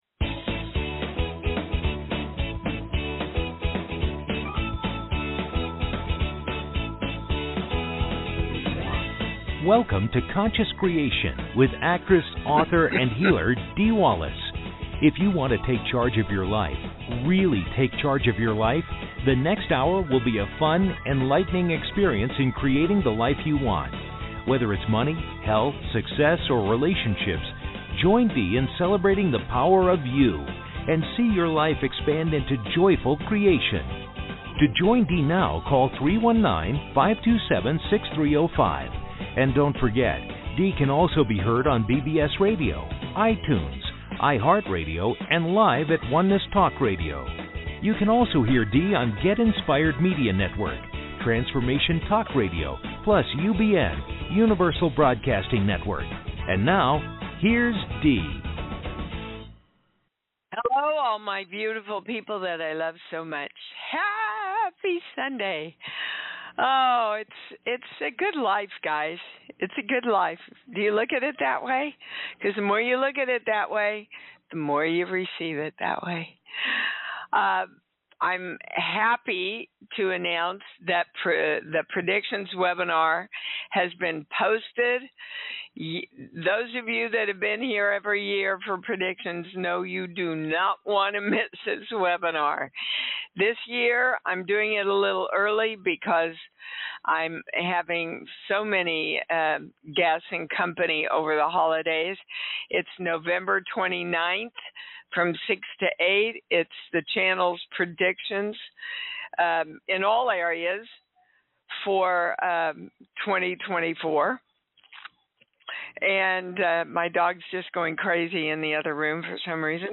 Subscribe Talk Show Conscious Creation Show Host Dee Wallace Dee's show deals with the latest energy shifts and how they correspond with your individual blocks.